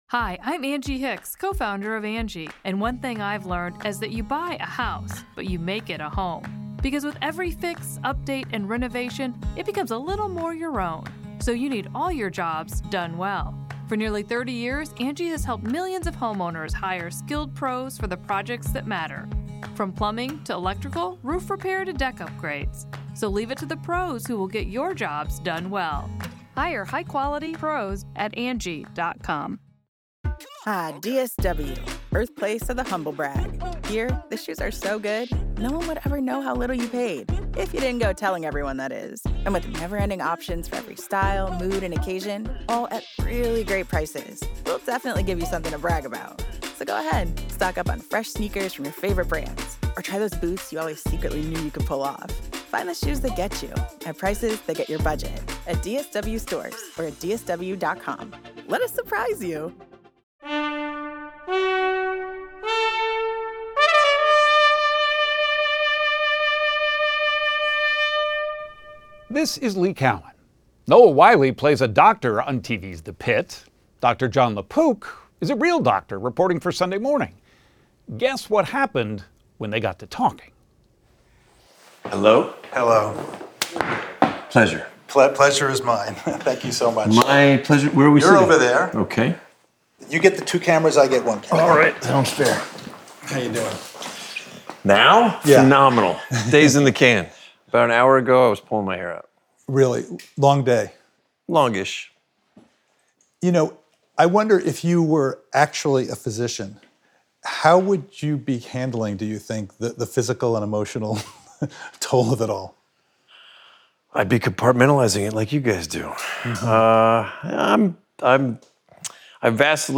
Extended Interview: Noah Wyle